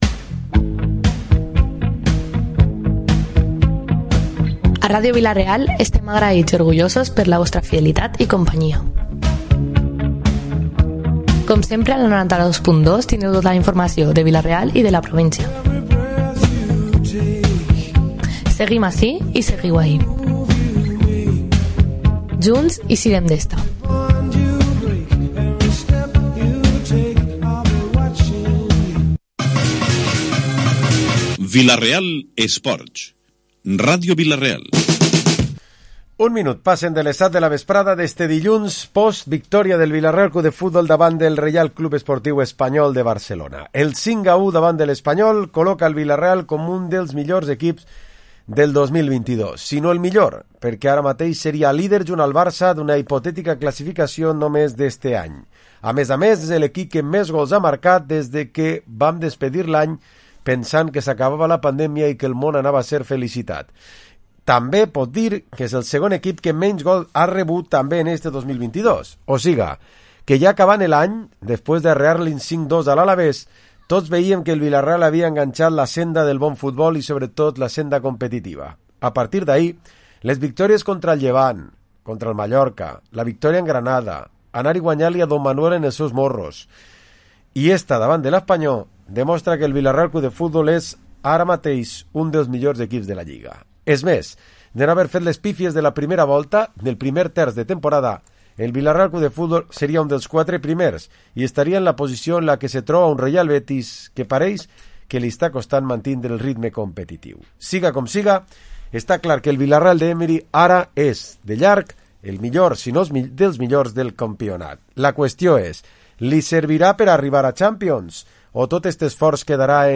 Programa esports tertúlia dilluns 28 de Febrer